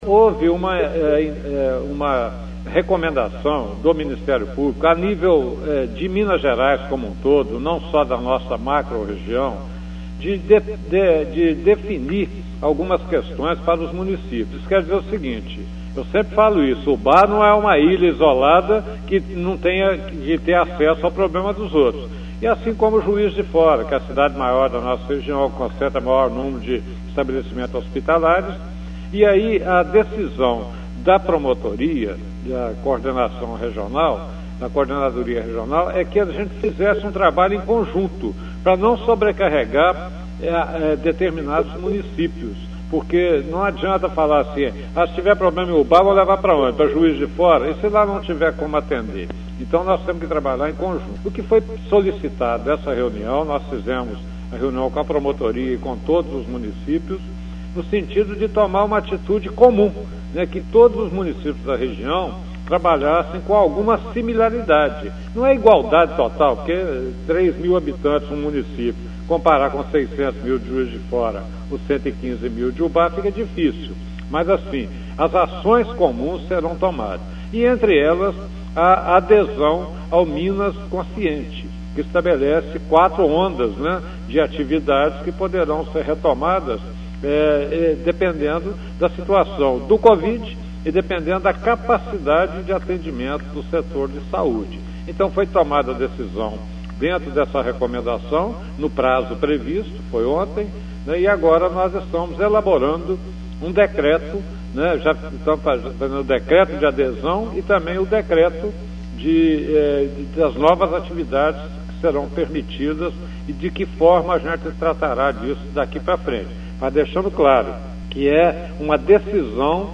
Aperte o play e ouça o Prefeito.
áudio exibido na Rádio Educadora AM/FM Ubá-MG